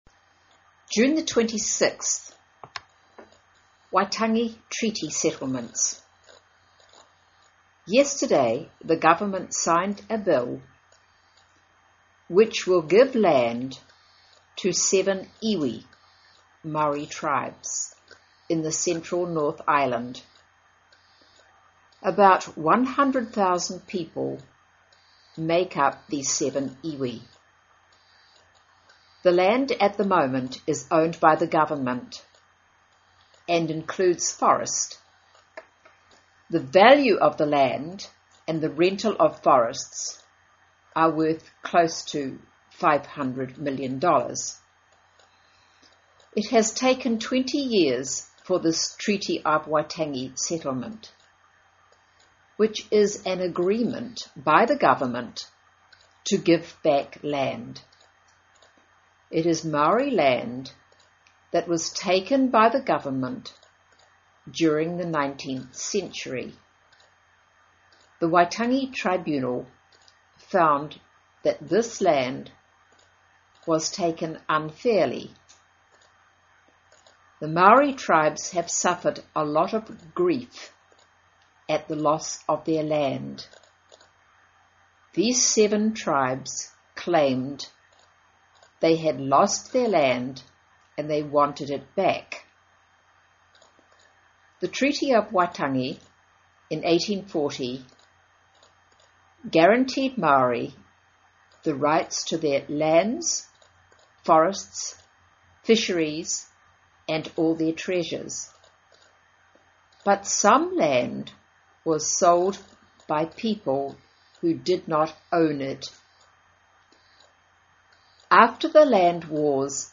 新西兰英语 38 Waitangi Treaty Settlements 听力文件下载—在线英语听力室